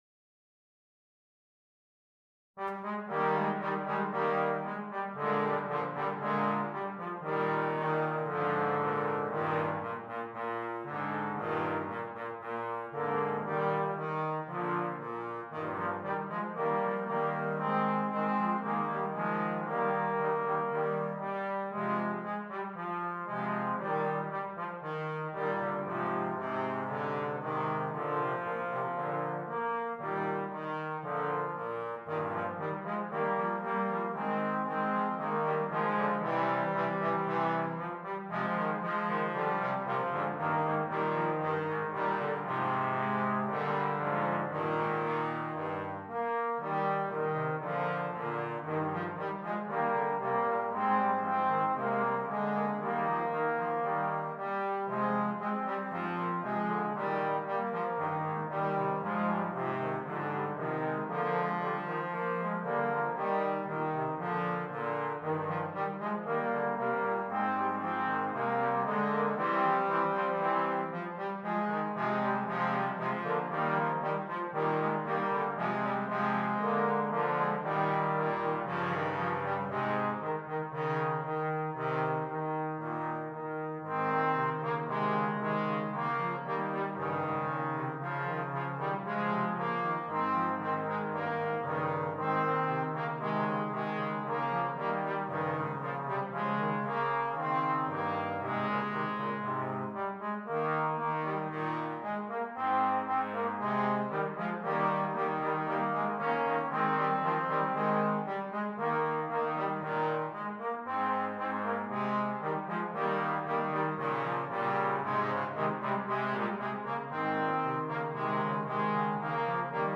3 Trombones